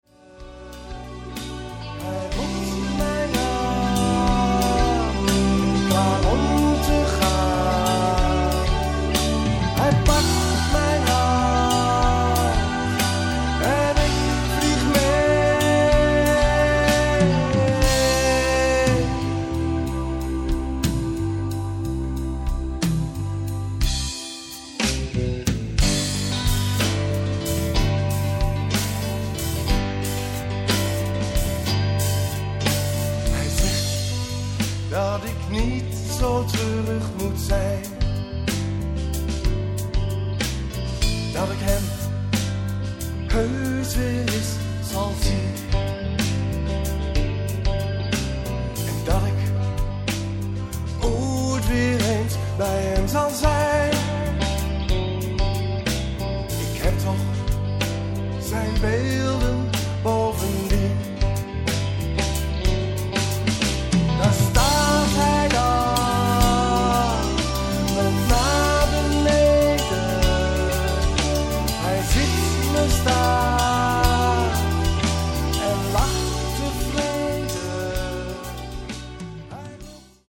Nederpop/rock
Zang
Gitaar
Toetsen
Drums